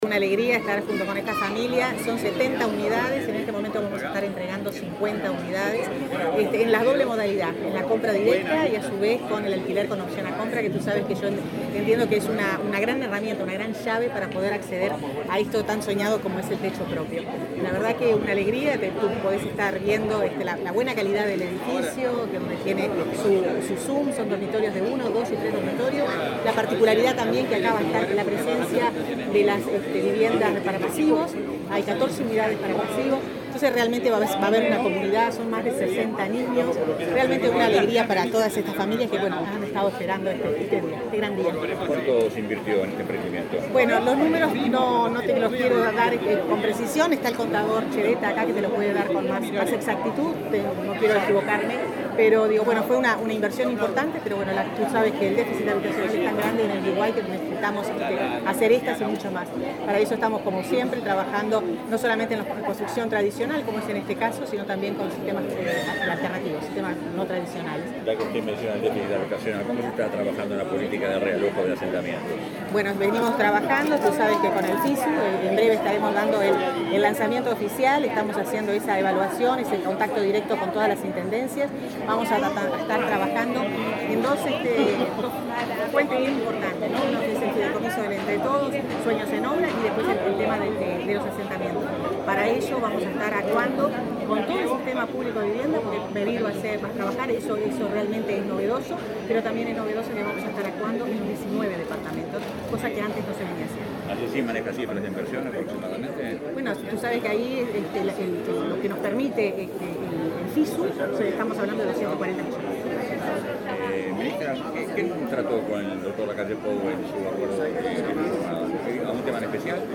Declaraciones a la prensa de la ministra de Vivienda, Irene Moreira
La ministra de Vivienda, Irene Moreira, entregó este viernes 8, soluciones habitacionales en Montevideo y, luego, dialogó con la prensa.